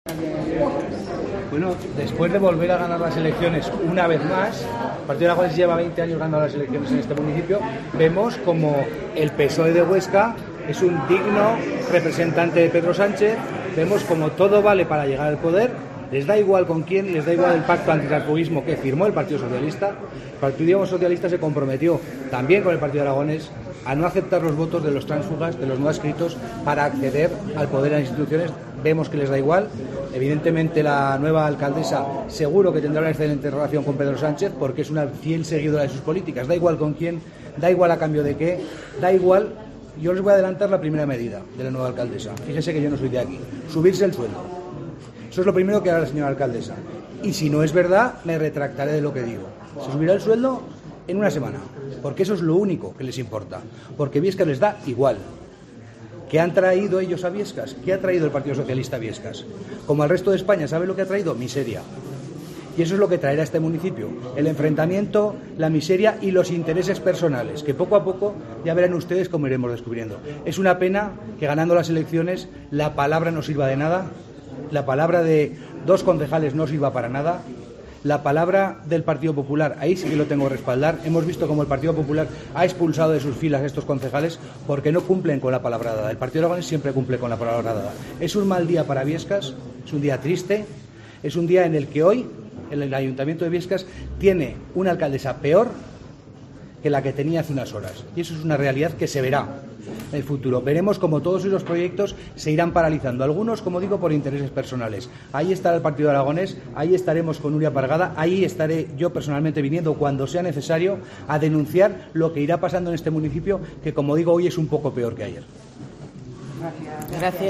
Izquierdo se ha manifestado así ante los medios de comunicación en Biescas, donde ha asistido al pleno en el que ha salido adelante la moción de censura contra la alcaldesa del PAR